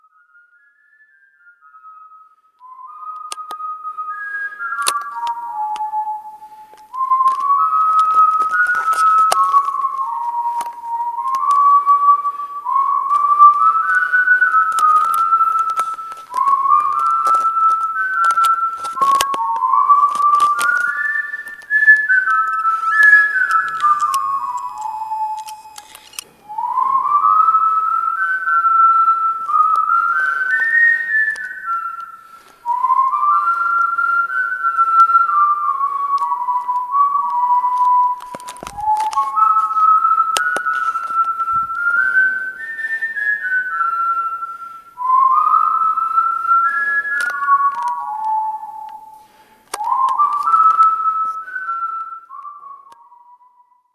Echo berühmten Sönghellir.